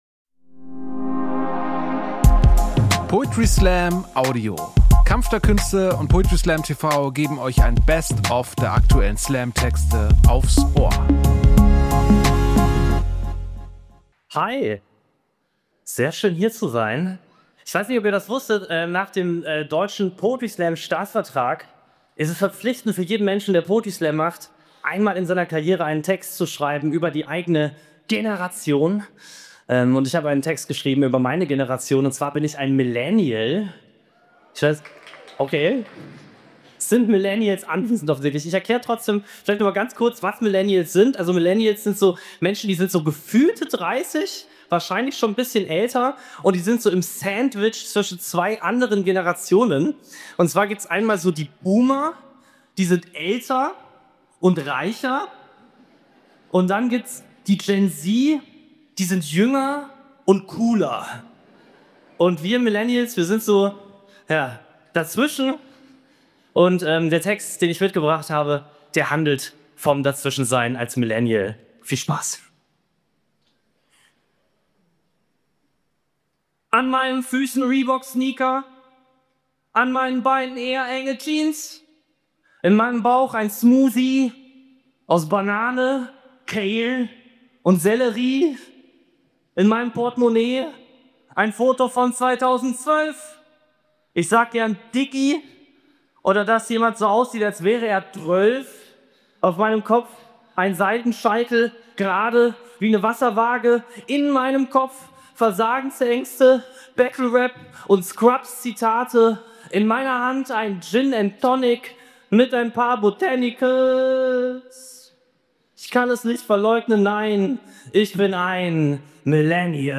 Stage: Elbphilharmonie, Hamburg